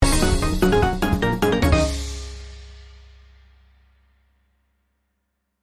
eff_bigwin.mp3